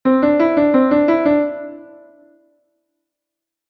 2/4: dous grupos de semicorcheas iguais